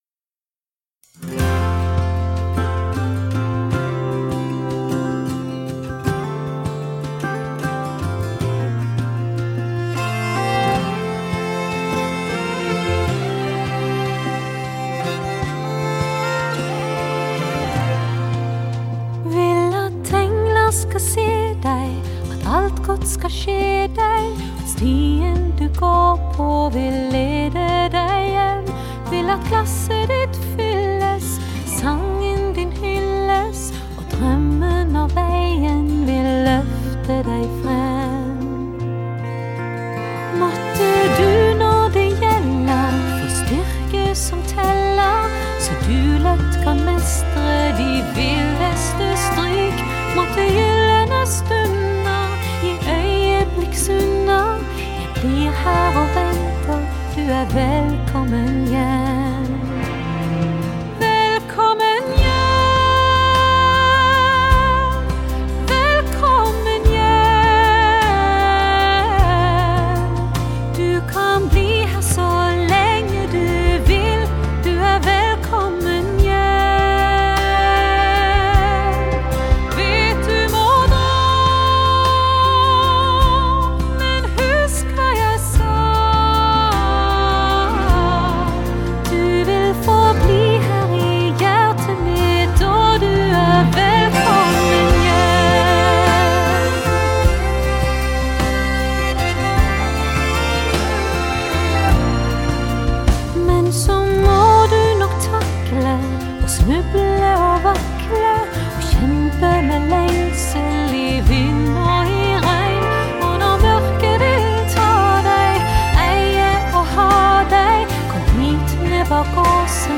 这张专辑包含的歌曲分别是5首瑞典语、3首丹麦语、2首英语，感觉更偏向于民谣风格。